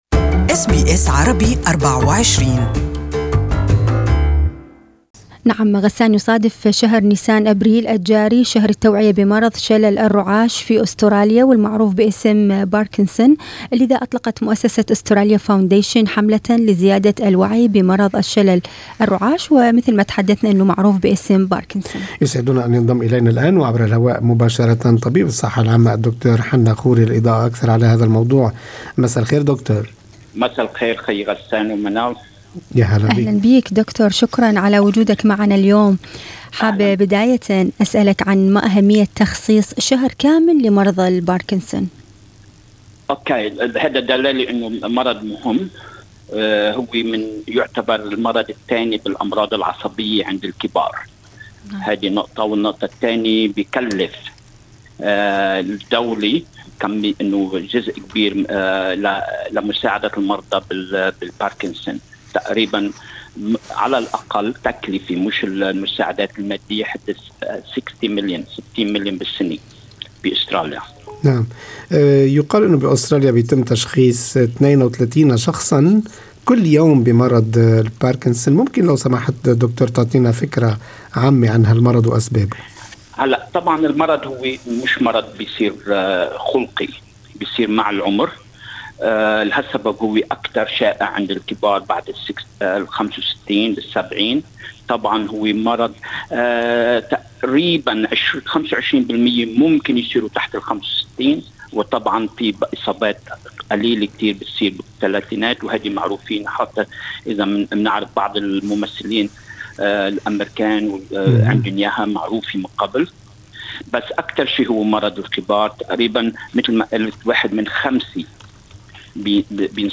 استمعوا إلى اللقاء مع طبيب الصحة العامة